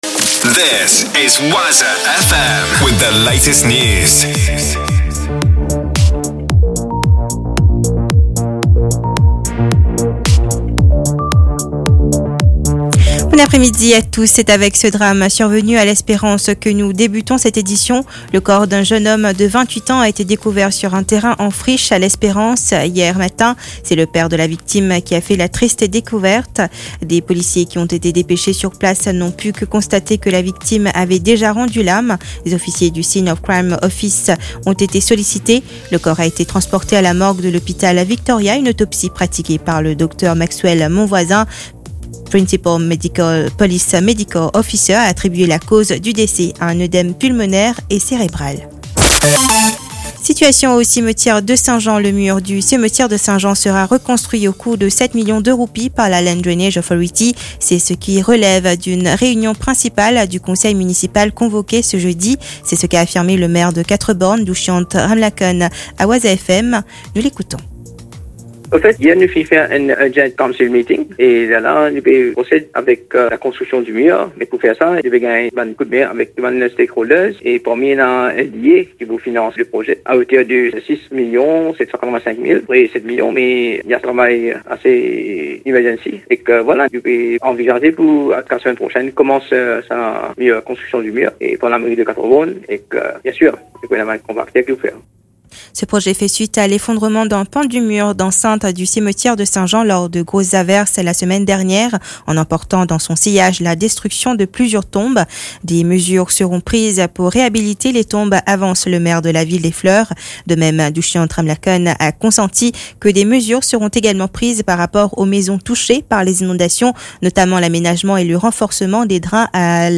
NEWS 16H - 17.11.23